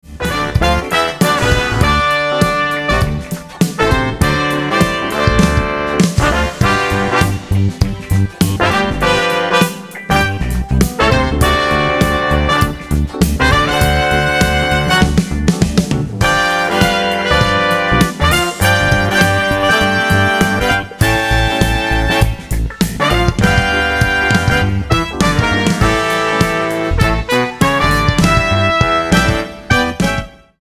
200 BPM